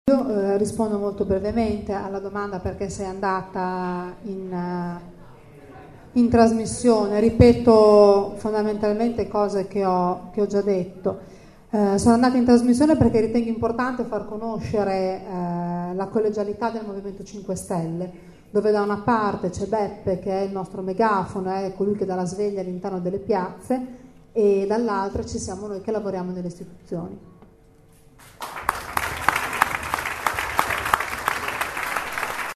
E Salsi non si è sottratta, ha risposto e ha ricevuto il lungo applauso dalla stragrande maggioranza dell’assemblea.